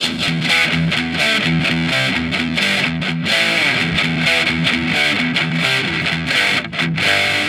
Guitar Licks 130BPM (15).wav